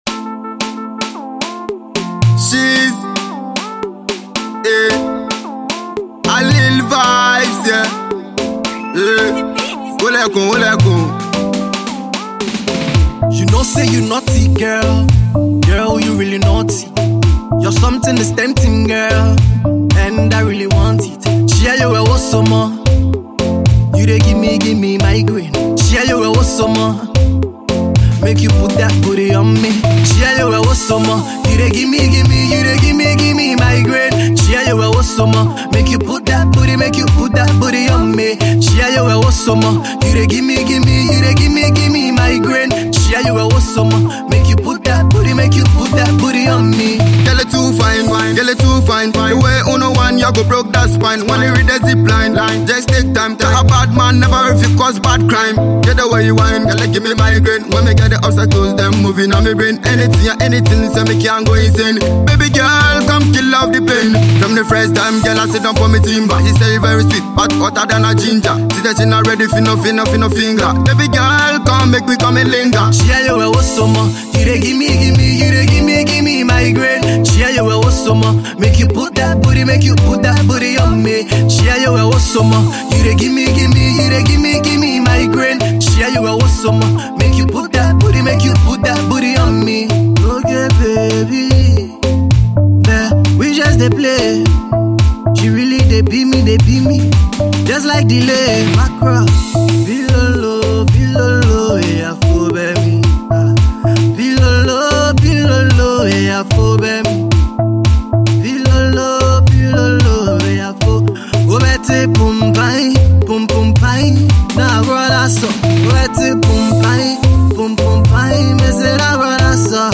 a danceable Afropop tune.